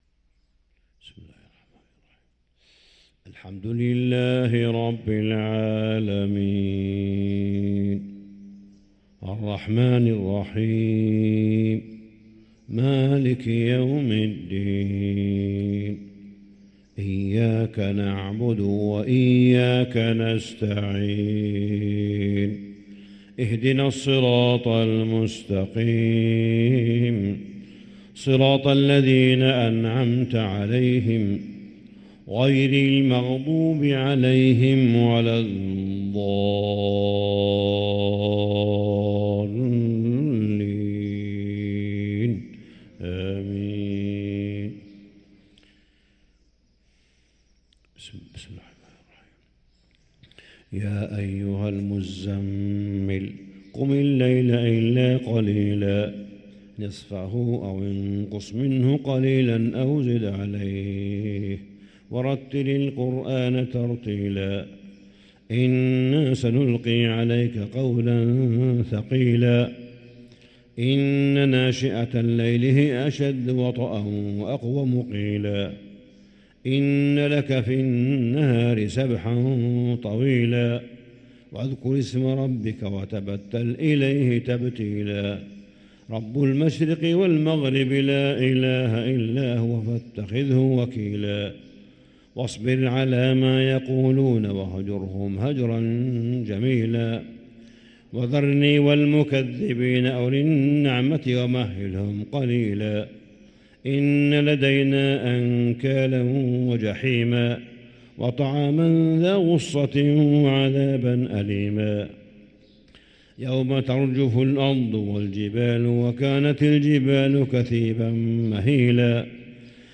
صلاة الفجر للقارئ صالح بن حميد 11 جمادي الآخر 1445 هـ
تِلَاوَات الْحَرَمَيْن .